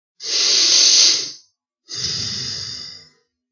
男声反应 " 叹息5男声深沉
描述：一个年轻的男性叹息，可能是沮丧，愤怒，厌倦，愤怒等。 使用我的Turtlebeach EarforceX12耳机录制，然后在Audacity中编辑。
标签： 男性 言语 呼吸 发声 叹息 男中音 声音 反应 呼吸
声道立体声